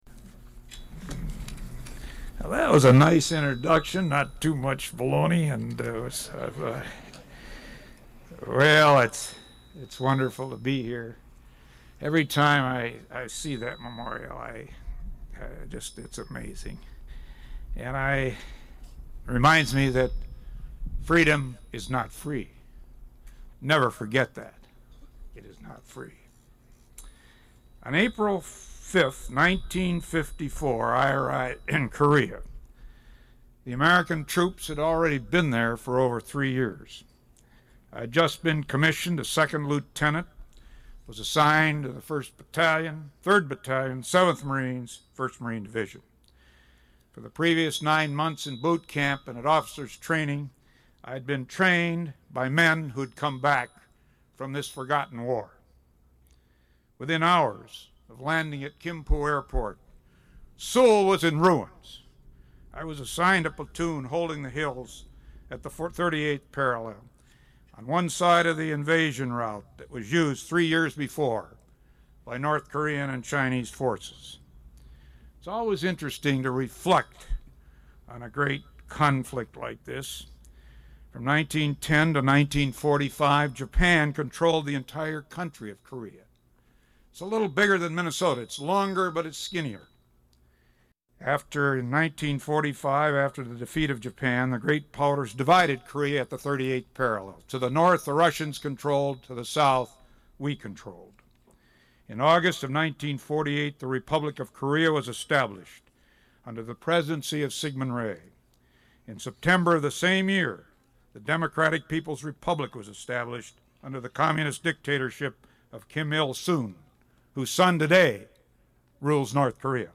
Location Internet Sandy Keith Veterans Day speech, Soldiers Field Veterans Memorial, recorded by KROC Radio, November 11, 2003. 1 master audio file (15 minutes, 48 seconds): WAV (159 MB) and 1 user audio file: MP3 (14.4 MB).